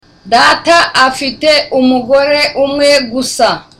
(Looking serious)